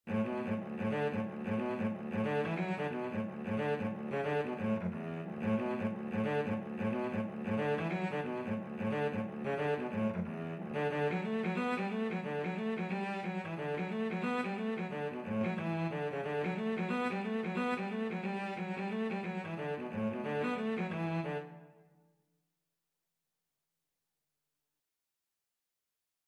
D major (Sounding Pitch) (View more D major Music for Cello )
4/4 (View more 4/4 Music)
Cello  (View more Easy Cello Music)
Traditional (View more Traditional Cello Music)